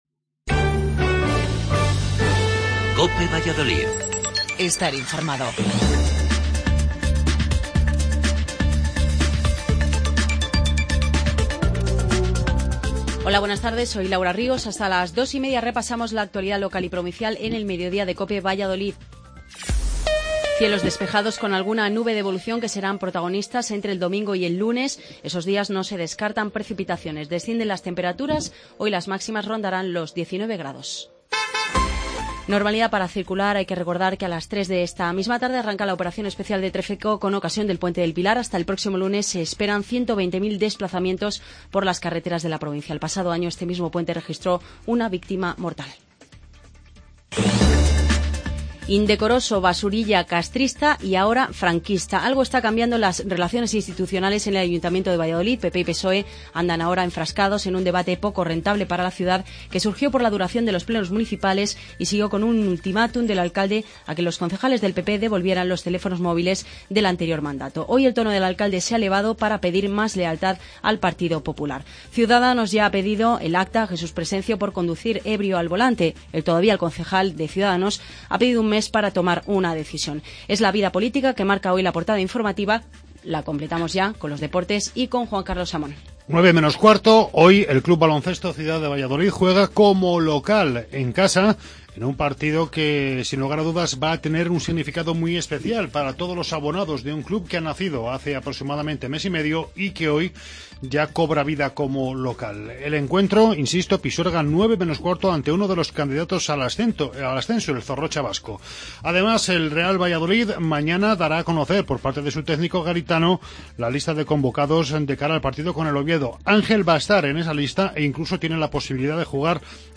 AUDIO: Informativo local